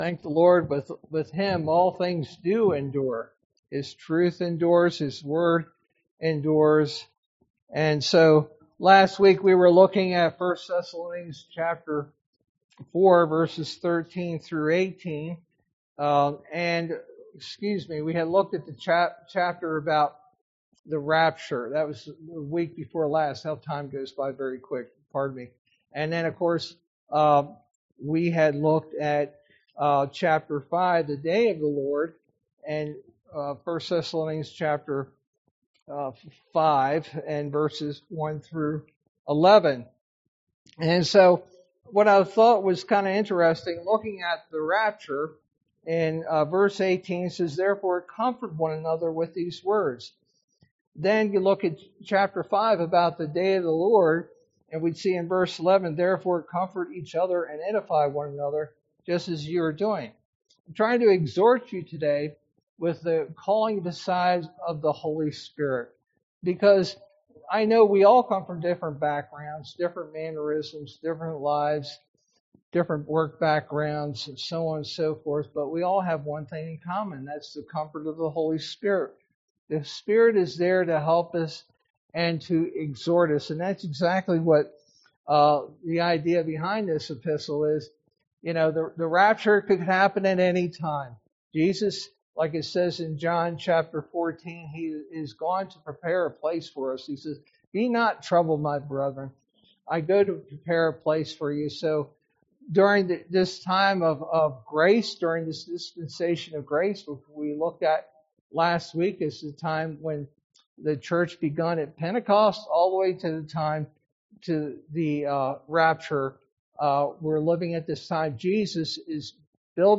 sermon verse: 1 Thessalonians 5:12-22